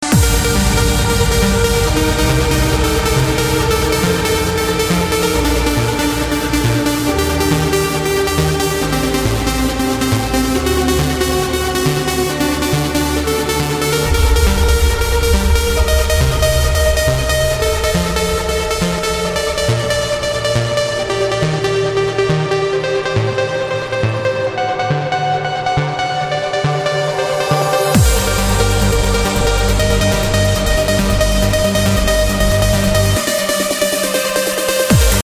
Категория: Танцевальные